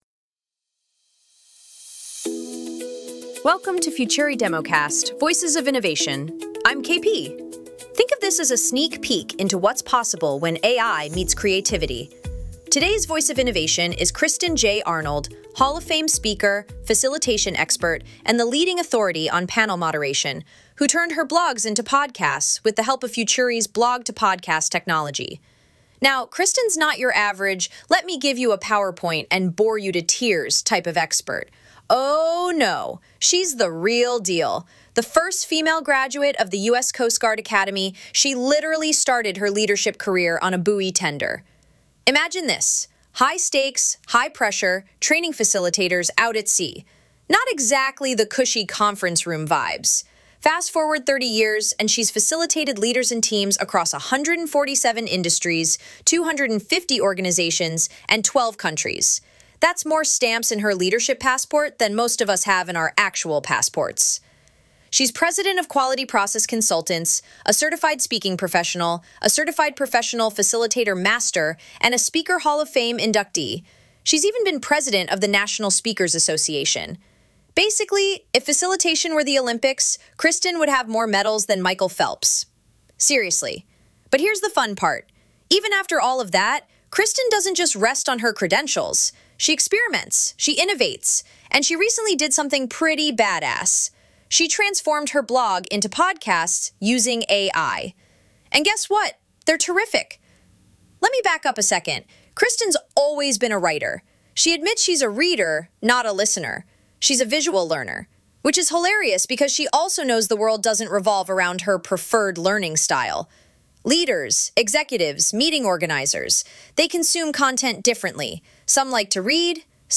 Instead of spending time recording, she now uploads her written posts, and AI generates natural, professional episodes in her own voice.
• Higher-quality audio that she admits “sounds better than me reading it myself.”